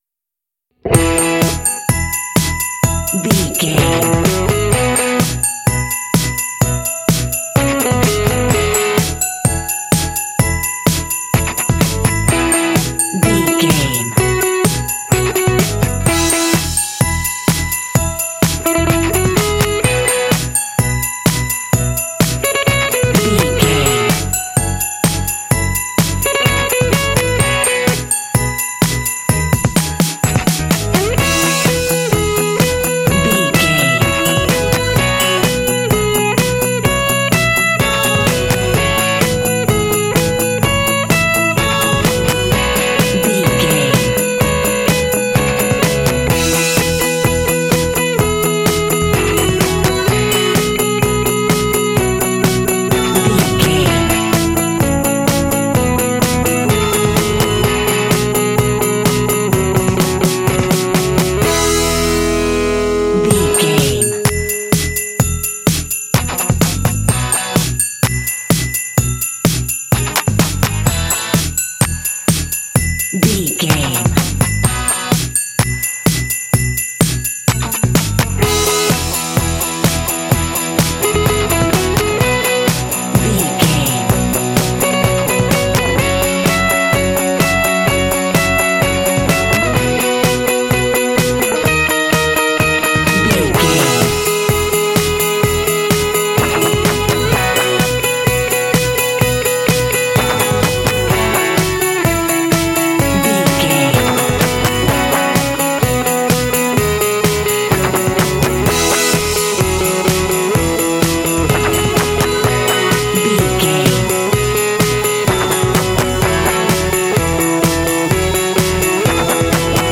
Ionian/Major
playful
festive
happy
drums
electric guitar
bass guitar
pop
contemporary underscore